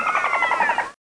dolphin2.mp3